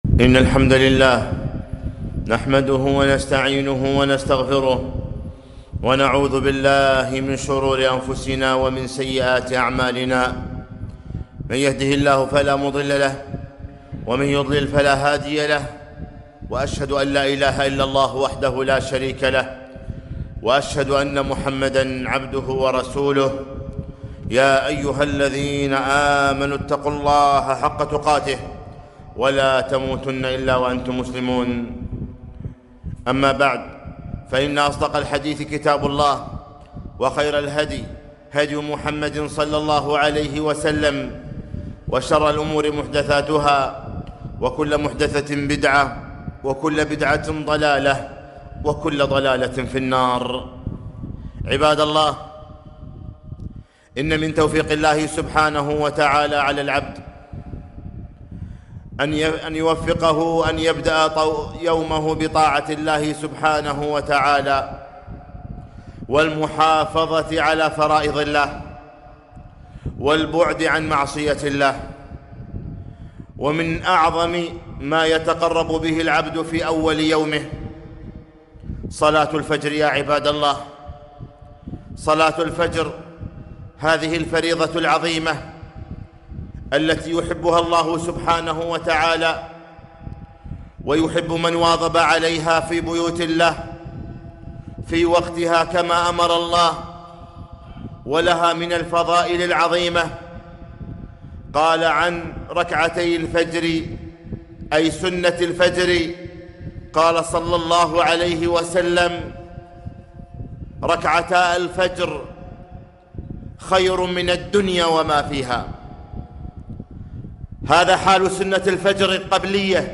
خطبة - رسالة لمن نام عن صلاة الفجر 22صفر1442